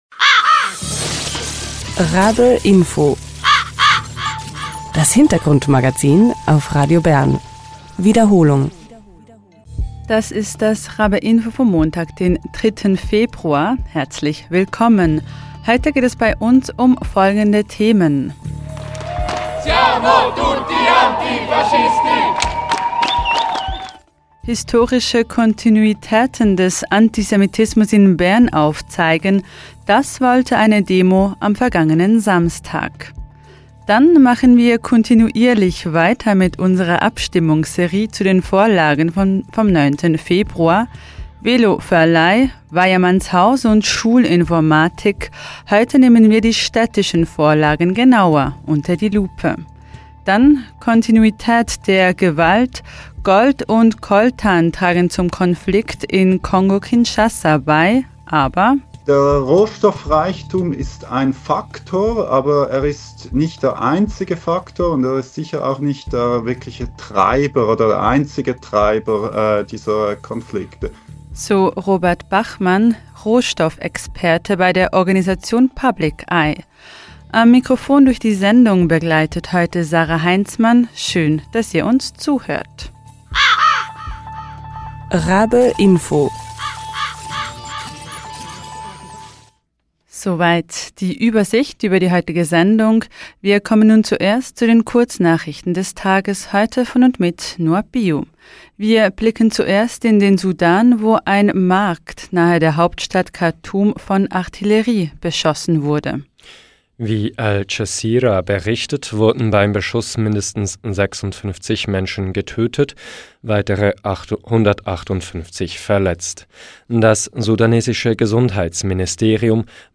Historische Kontinuitäten des Antisemitismus in Bern aufzeigen: Das wollte eine Demo am vergangenen Samstag, wir waren vor Ort. Dann machen wir kontinuierlich weiter mit unserer Abstimmungsserie zu den Vorlagen vom 9. Februar.